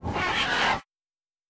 ENEMY_FILTH_ATTACK.ogg